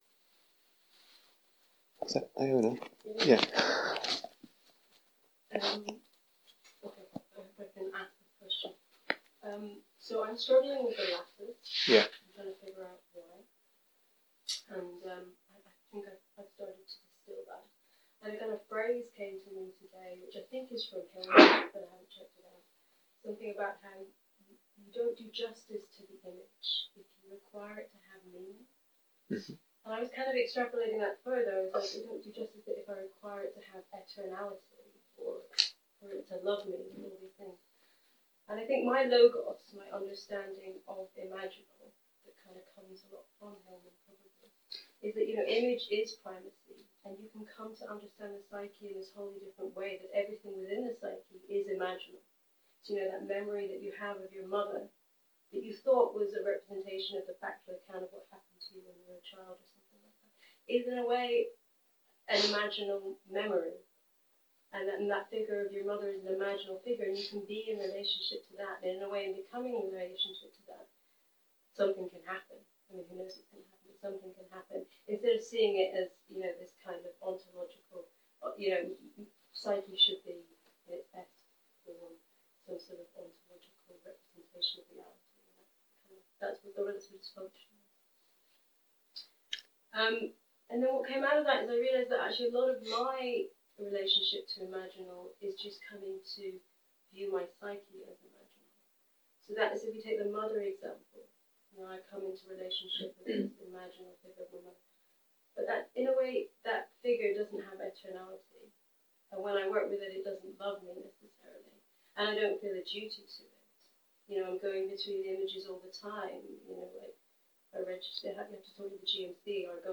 Here is the full retreat on Dharma Seed (Freely Given Retreats) The talks and exercises from this 'Tending the Holy Fire' retreat are intended for experienced practitioners who already have a working familiarity with this particular Soulmaking paradigm, as outlined, for example, in the following retreats: 'The Path of the Imaginal (Longer Course)'; 'Re-enchanting the Cosmos: The Poetry of Perception'; and 'Of Hermits and Lovers: The Alchemy of Desire'.